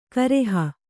♪ kareha